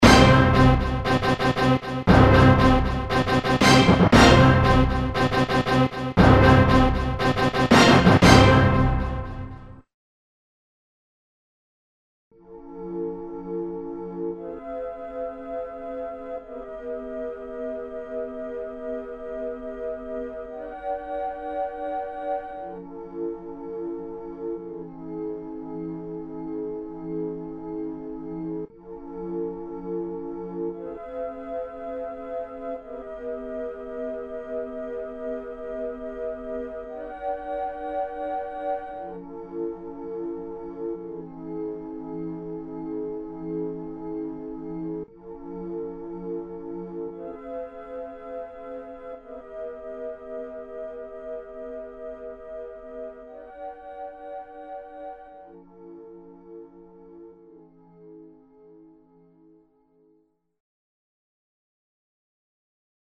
orchestral and rock tunes